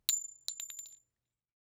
CassingDrop 04.wav